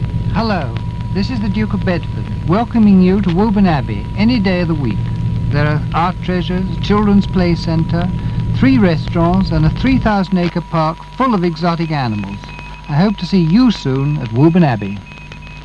click to hear audio Radio Caroline's first commercial, voiced by the Duke of Bedford, advertising his home, Woburn Abbey.